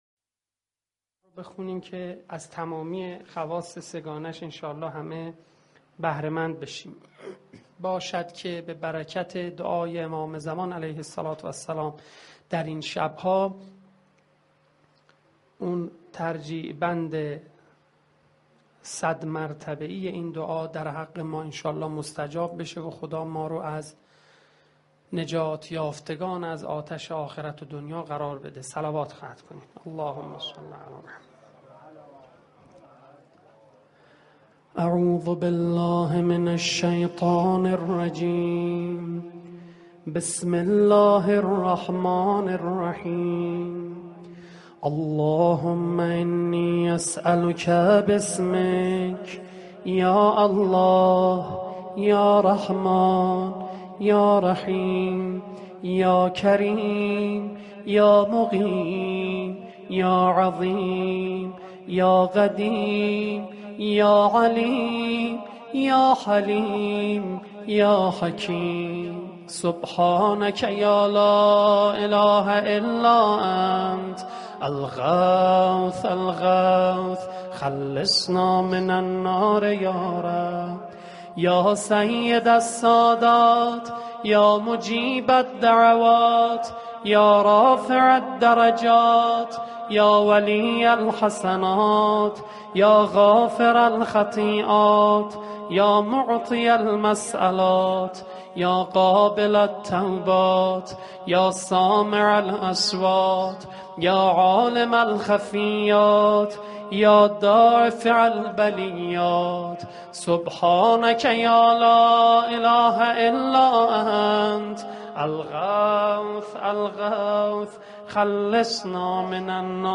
دعای جوشن کبیر با صدای